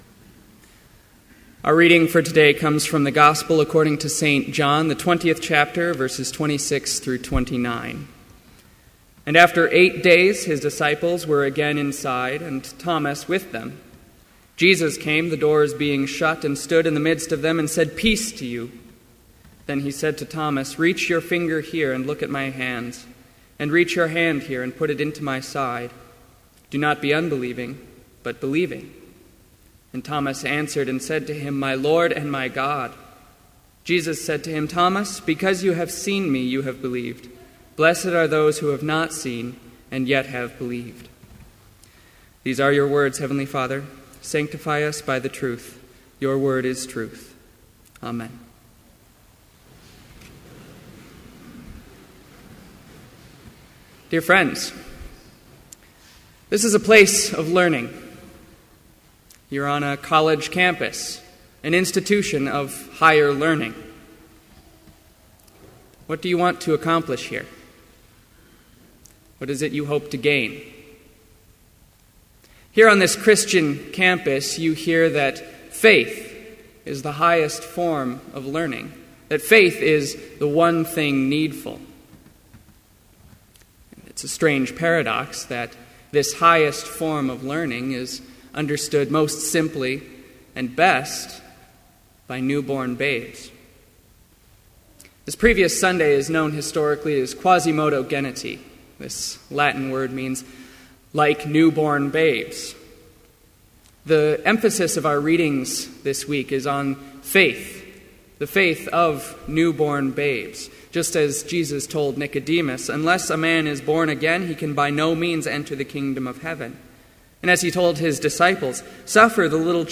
Complete Service
• Hymn 361, vv. 1-4, Faith Is a Living Power
• Homily
This Chapel Service was held in Trinity Chapel at Bethany Lutheran College on Tuesday, April 29, 2014, at 10 a.m. Page and hymn numbers are from the Evangelical Lutheran Hymnary.